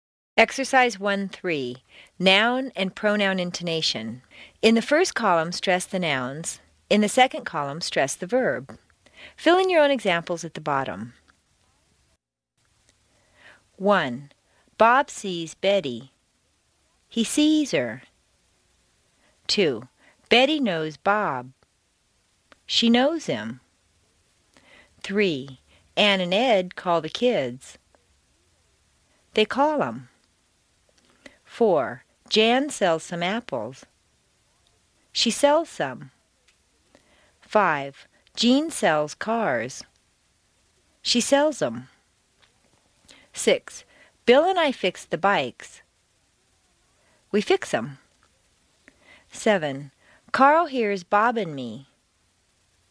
美式英语正音训练第6期:名词和代词的语调 听力文件下载—在线英语听力室